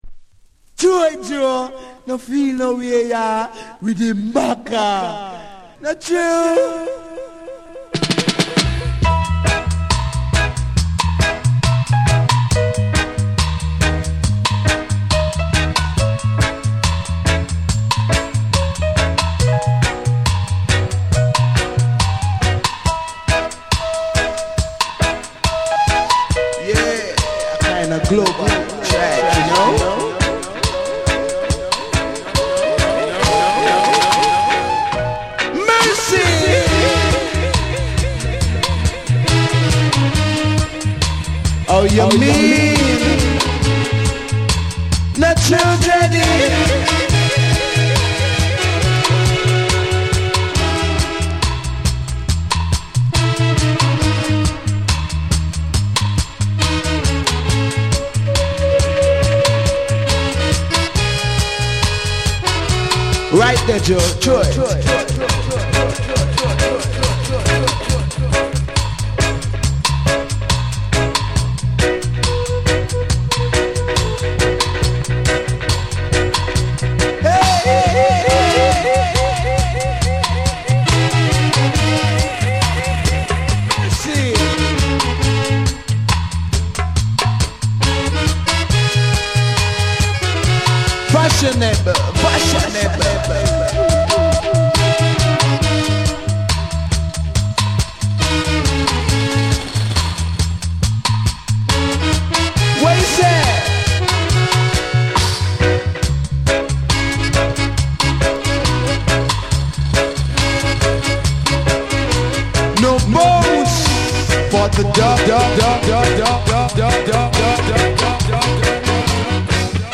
重厚なベースと鋭いカッティング、ダイナミックなミキシングが際立つ70年代ミキシング・ダブの真髄を収録。
REGGAE & DUB